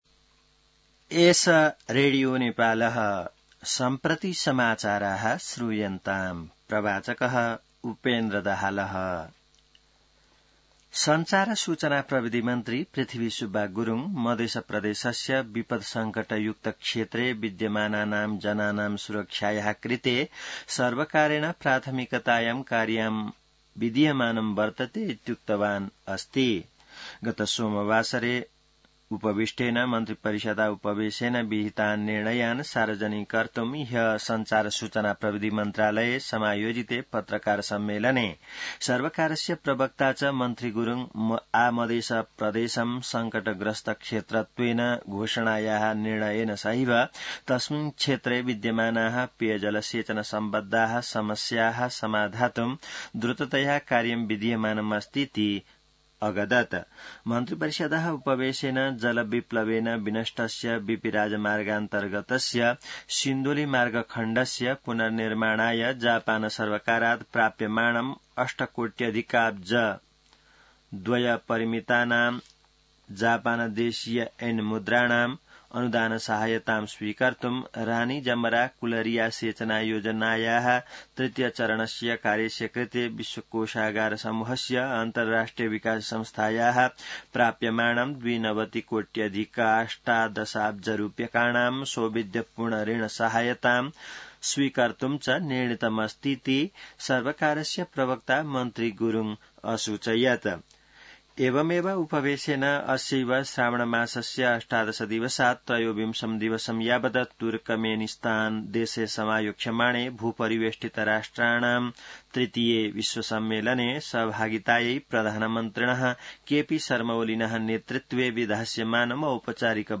संस्कृत समाचार : १६ साउन , २०८२